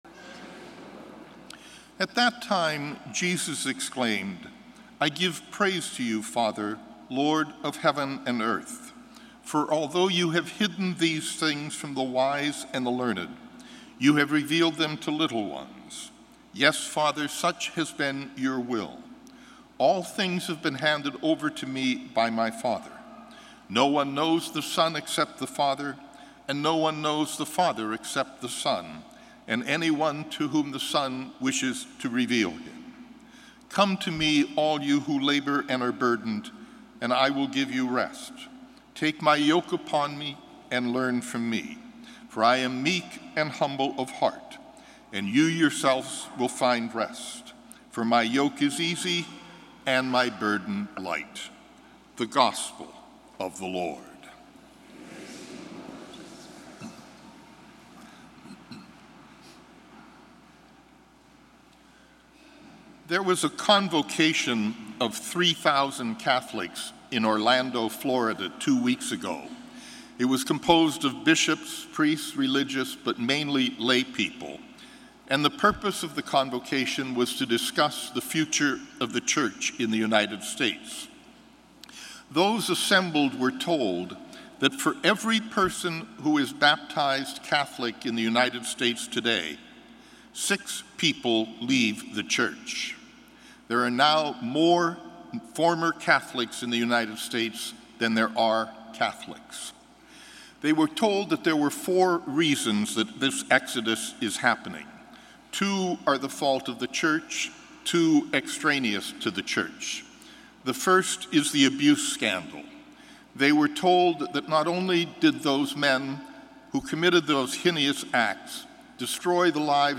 Gospel & Homily July 9, 2017